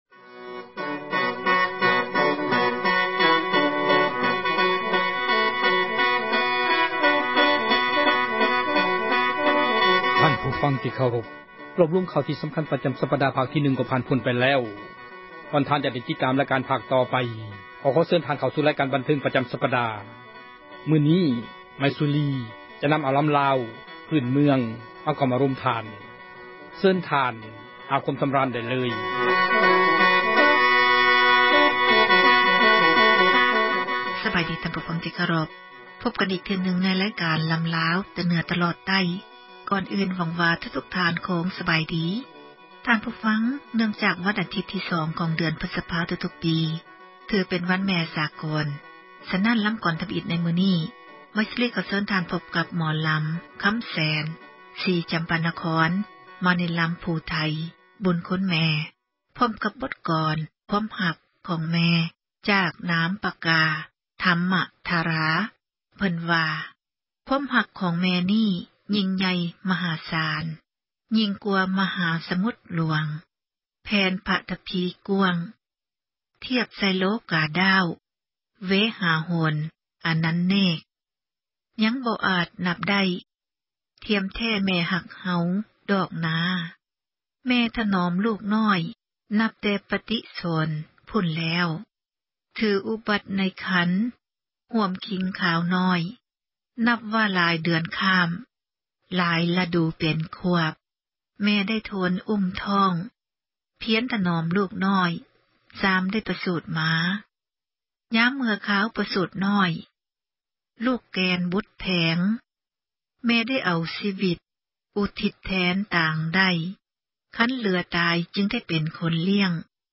ຣາຍການ ໝໍລຳລາວ ປະຈຳ ສັປດາ ຈະນໍາເອົາ ສິລປະ ການຂັບລໍາ ທີ່ເປັນມູນ ມໍຣະດົກ ຂອງລາວ ໃນແຕ່ລະ ຊົນເຜົ່າ ແຕ່ລະ ພາກພື້ນເມືອງ ທີ່ເຮົາຄົນລາວ ຈະຕ້ອງ ສົ່ງເສີມ ແລະ ອະນຸຮັກ ຕໍ່ໄປ.